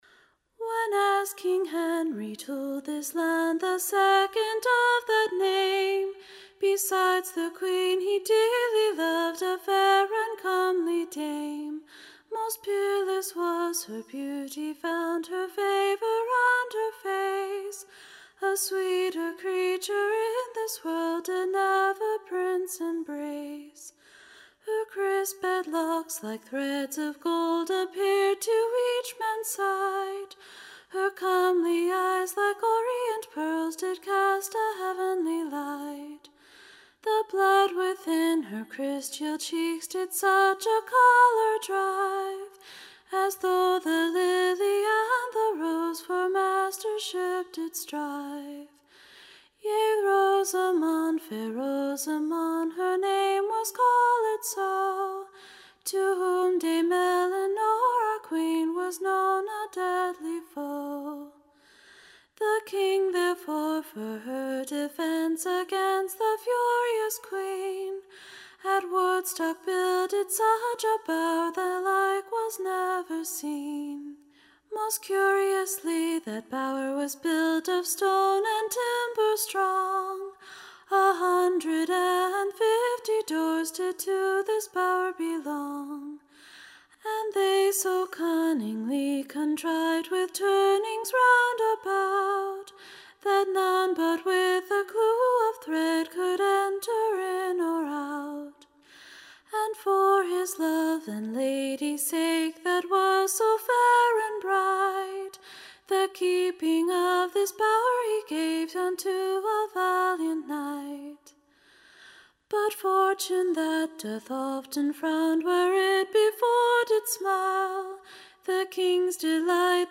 Recording Information Ballad Title A LAMENTABLE BALLAD OF / FAIR ROSAMOND, / Concubine to Henry 2nd.